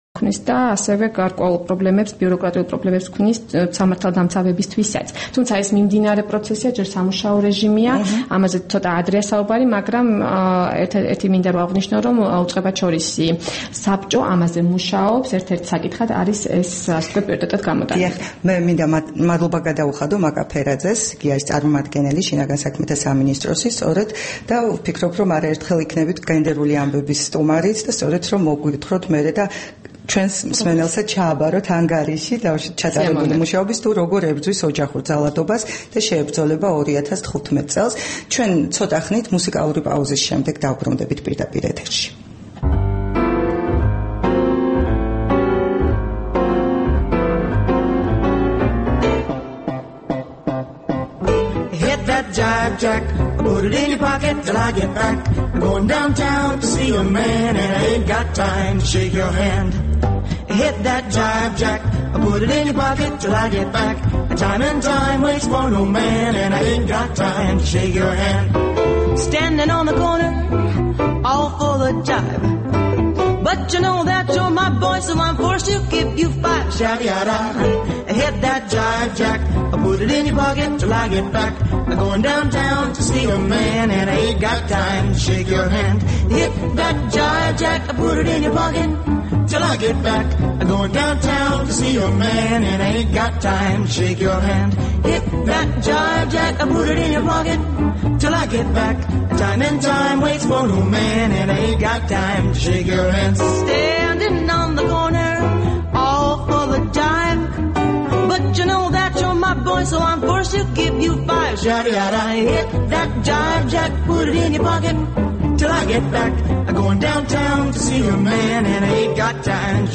რადიო თავისუფლების თბილისის სტუდიაში სტუმრად იყო
საუბარი